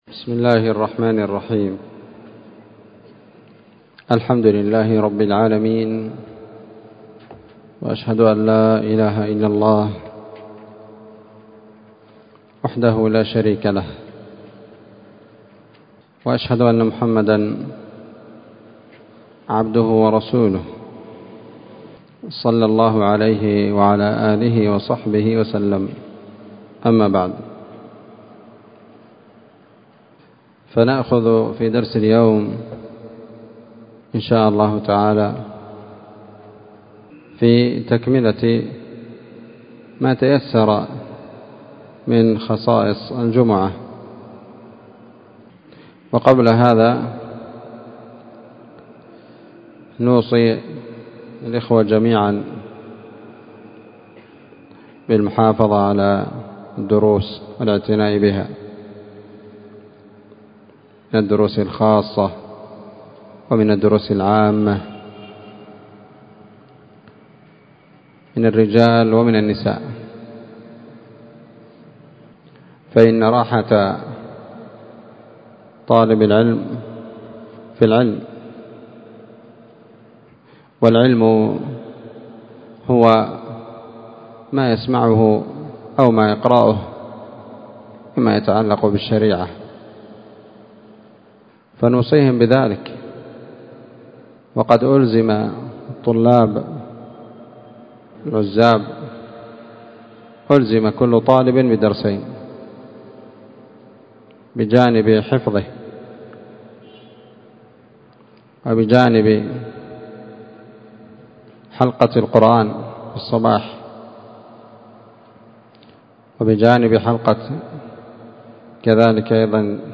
مسجد المجاهد- النسيرية-تعز